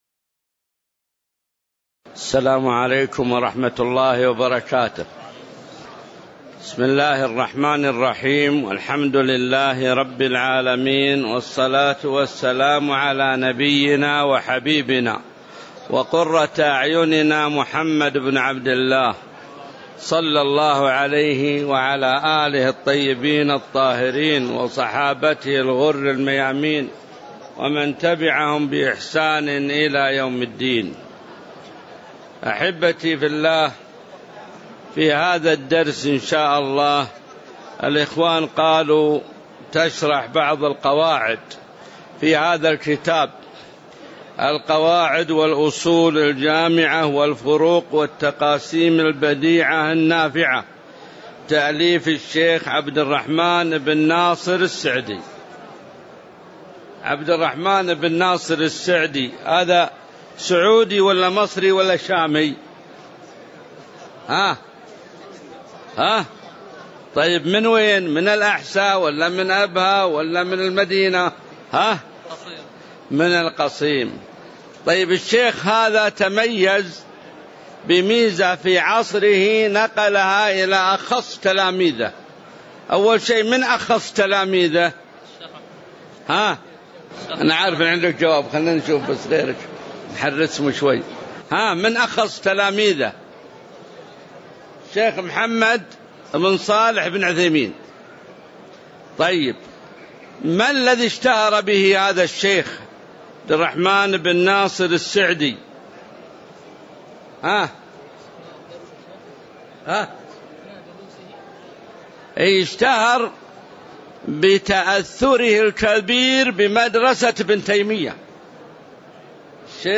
تاريخ النشر ١٠ جمادى الآخرة ١٤٣٨ هـ المكان: المسجد النبوي الشيخ: معالي الشيخ د. عبدالله بن محمد المطلق معالي الشيخ د. عبدالله بن محمد المطلق القاعدة الأولى (01)